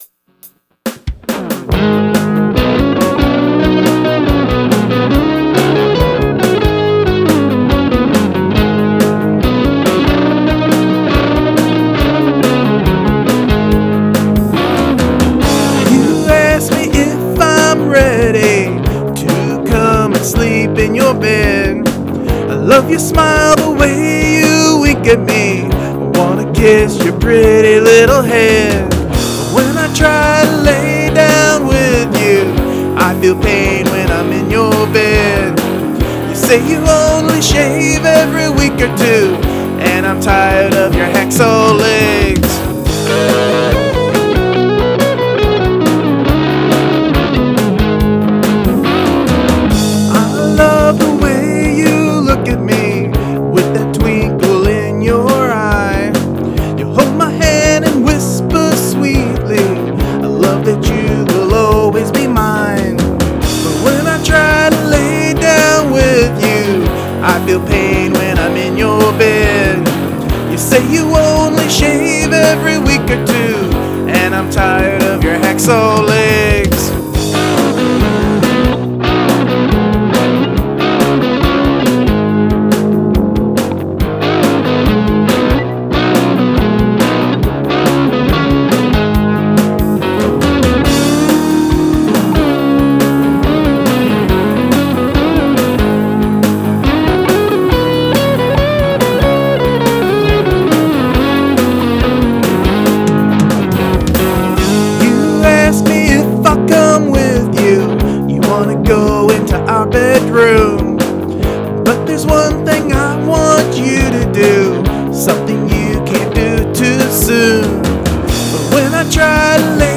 Rhythm Guitar/Lead Guitar: El Cheapo Johnson Strat Copy
Bass: El Cheapiero Squire Bass with faulty pots
Drums: El Free-o fake drums on BR-900
Vox: Mine on AT2020 condenser
All recording on the BR-900CD
Punkalicious.